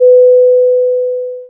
sinusfad.wav